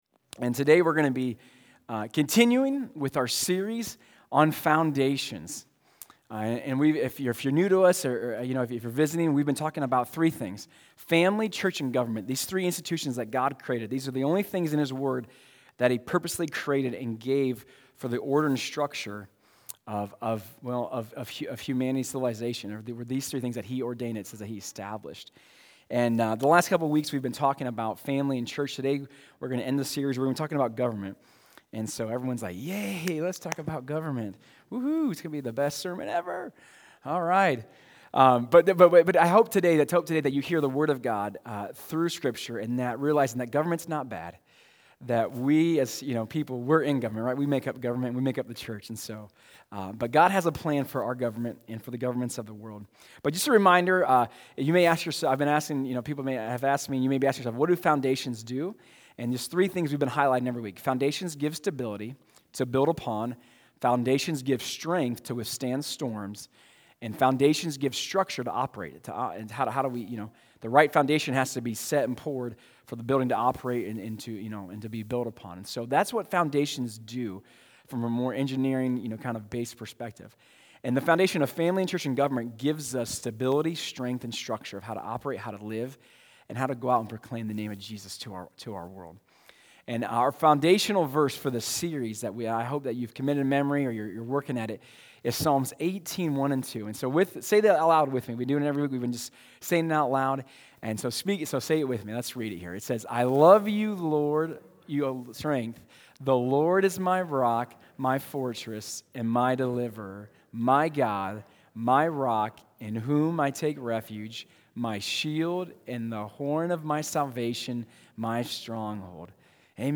Sermons | Crosspointe Church